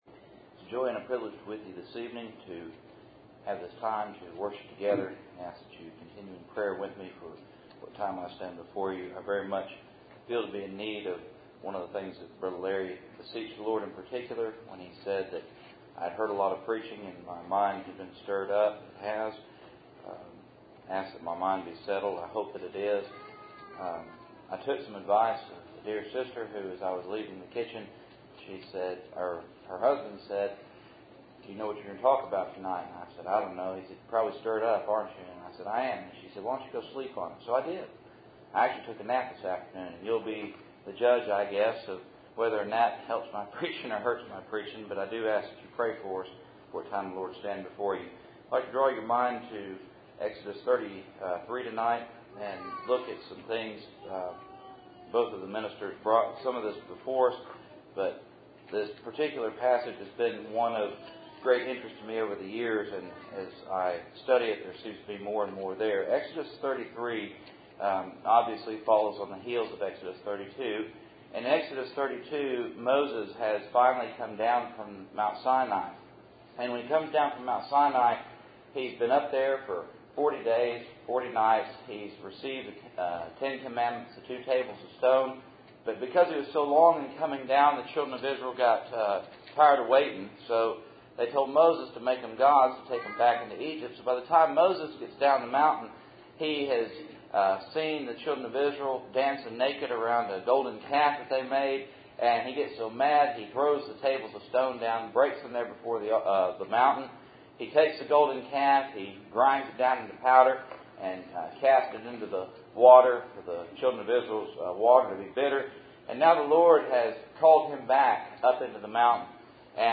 Cool Springs PBC August Annual Meeting